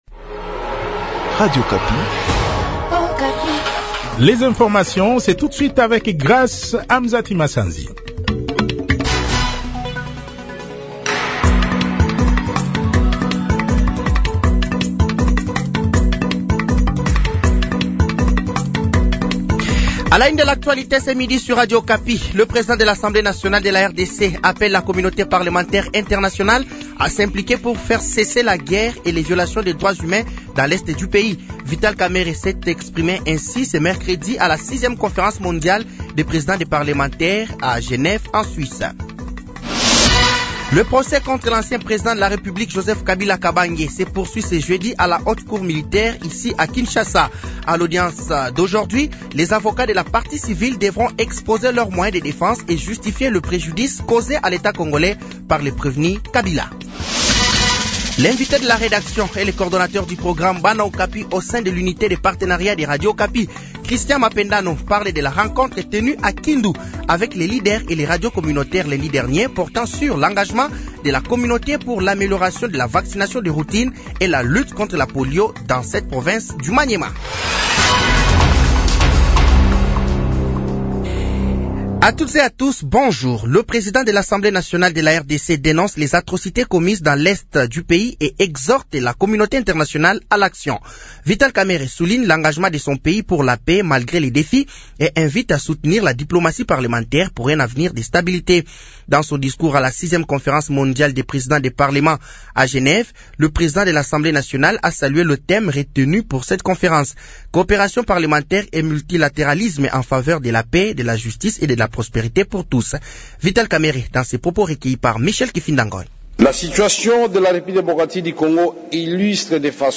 Journal français de 12h de ce jeudi 31 juillet 2025